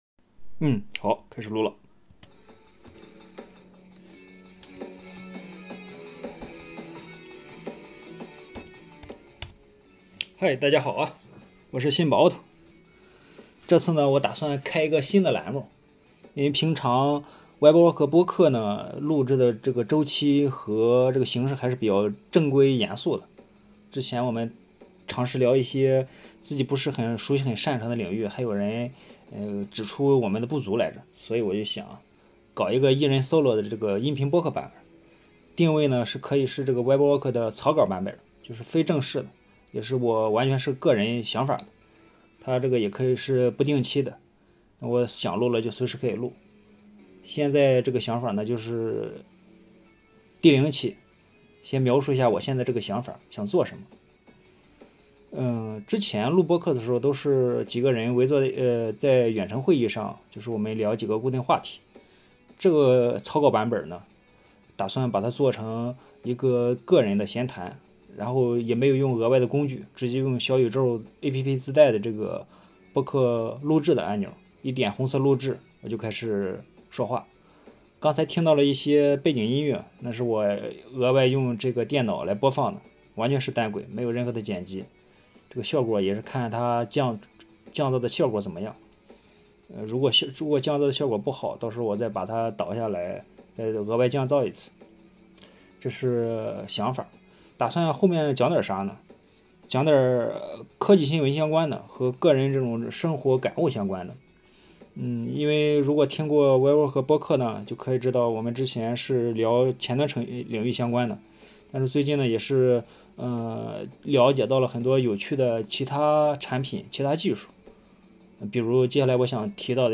因为 《Web Worker 播客》 还是相对认真、低频的，关注人数也多了，有一些不成熟的技术想法会有顾虑，不如新开个人solo音频播客，草稿版本的 Web Worker，主打随意和快速消费。